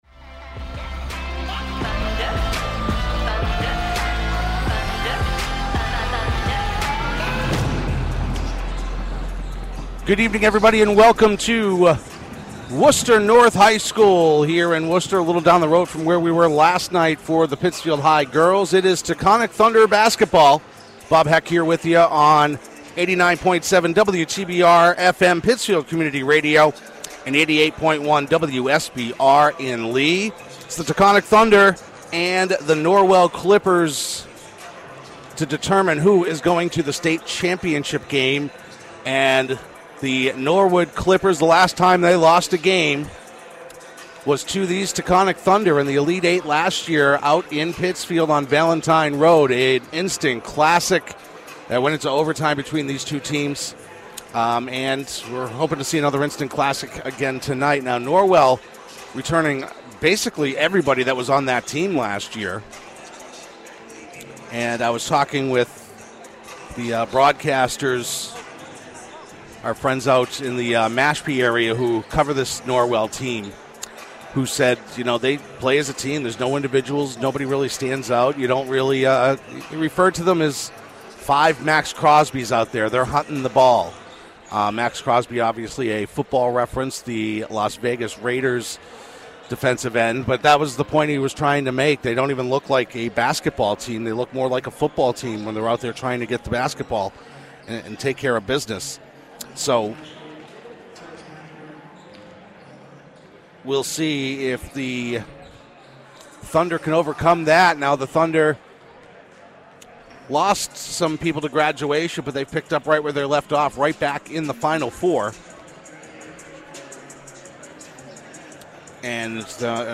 PCTV Sports and WTBR travelled to Worcester North High School to bring you live action between the Taconic Boys team and Norwell in the Division III Final Four.